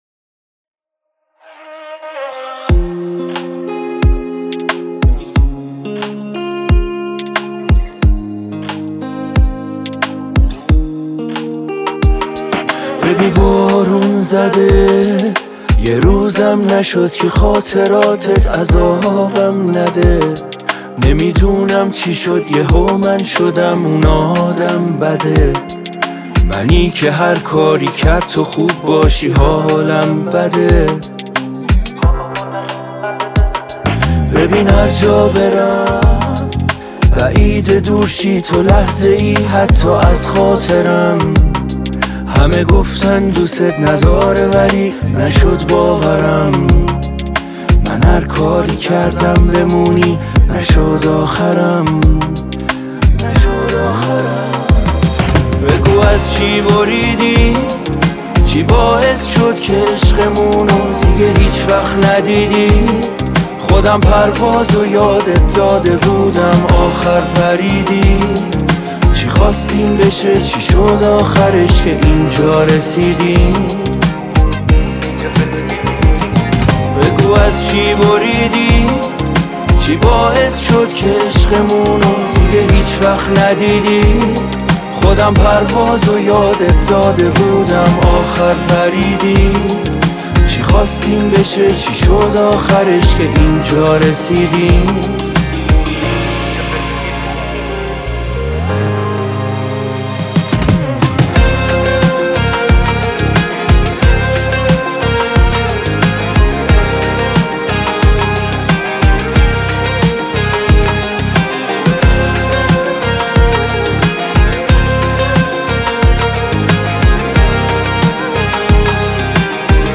عاشقانه و احساسی